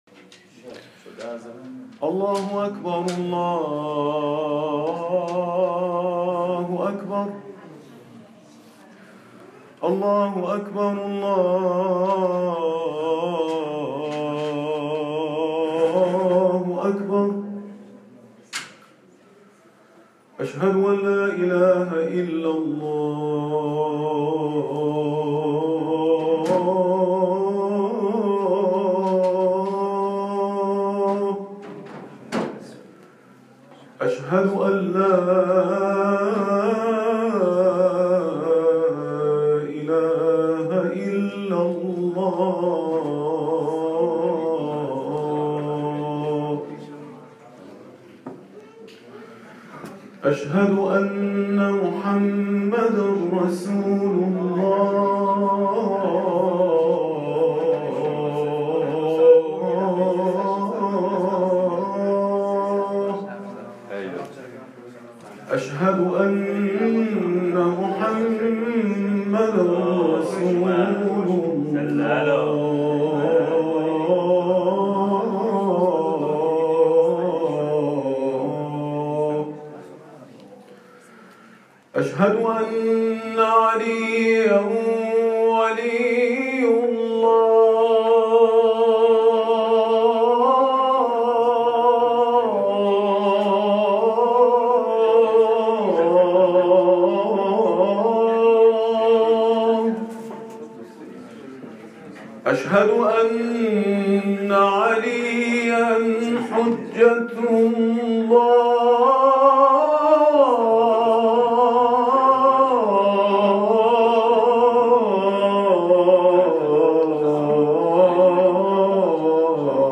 تلاوت اذانگاهی شب 19 ماه مبارک رمضان در انجمن دانشجويان ايراني